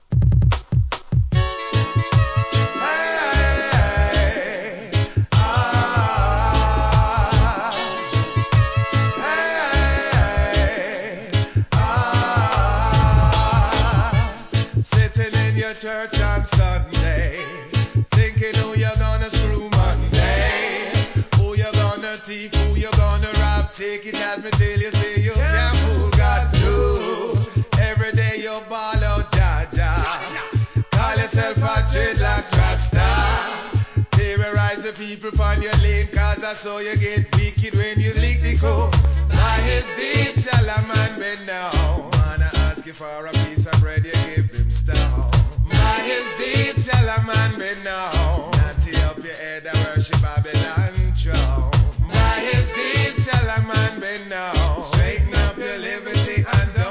REGGAE
素晴らしいヴォカール・ワークでのスマッシュHIT!!